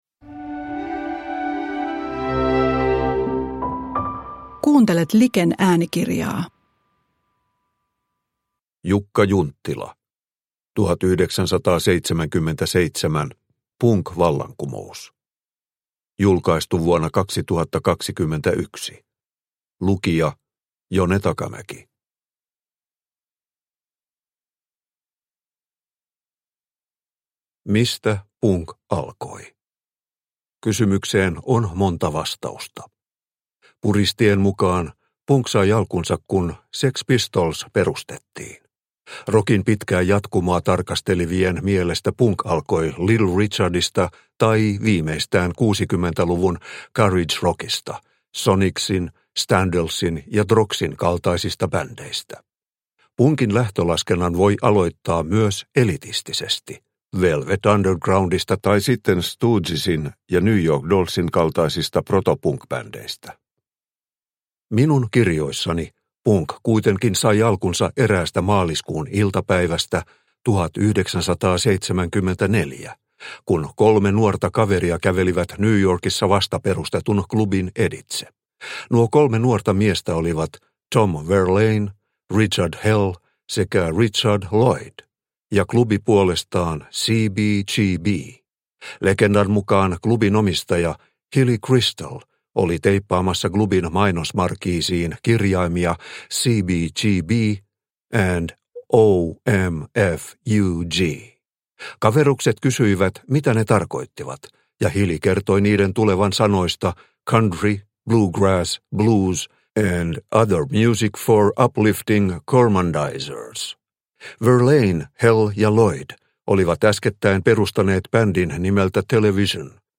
1977 - Punkvallankumous – Ljudbok – Laddas ner